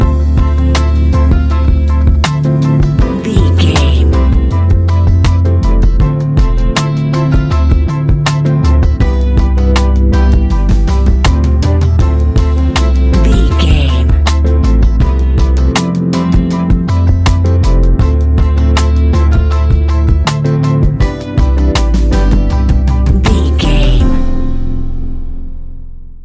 Ionian/Major
laid back
Lounge
chilled electronica
ambient